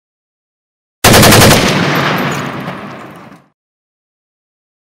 دانلود صدای تیراندازی 4 از ساعد نیوز با لینک مستقیم و کیفیت بالا
جلوه های صوتی